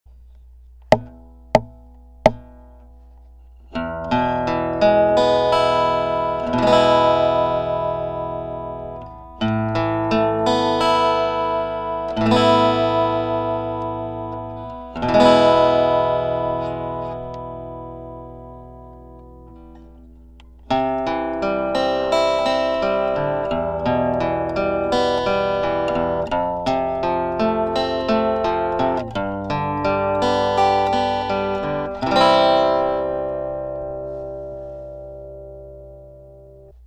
・ピエゾの出力を録音する（今回はBOSSのBR-600を使用）
１５じゃ、リバーブ感あんまりですね。
ピエゾ小リバーブ１５
SMALL_REVEFFECT.mp3